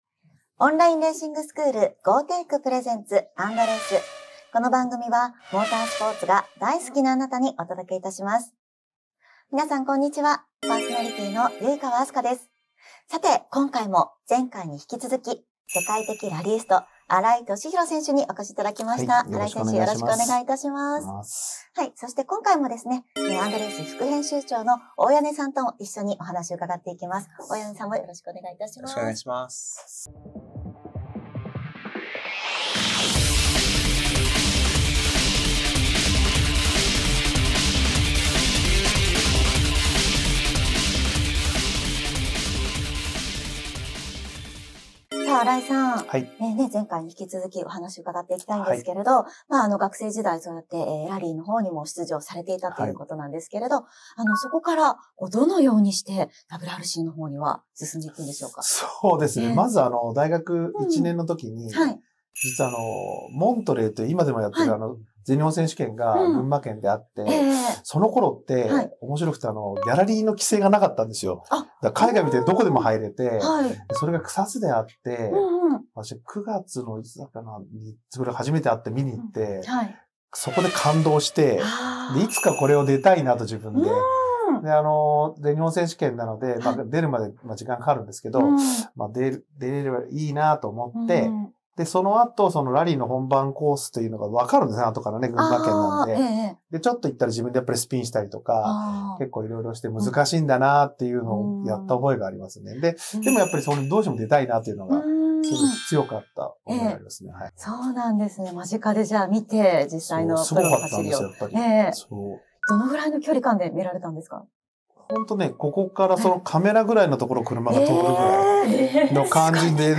今回の＆Raceも、世界的ラリースト新井敏弘選手をお迎えし、WRCへの軌跡を伺いました。